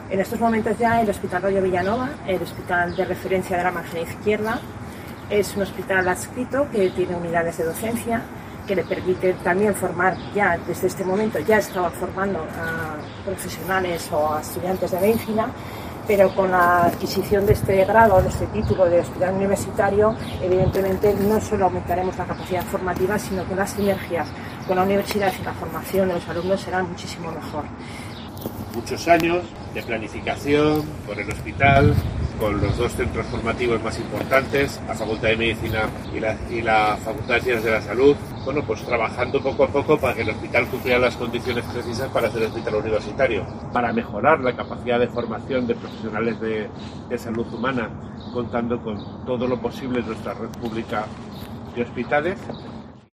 Declaraciones de la consejera de Sanidad y el rector de UNIZAR sobre el Hospital Universitario Royo Villanova.